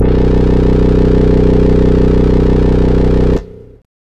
SYNTHBASS1-R.wav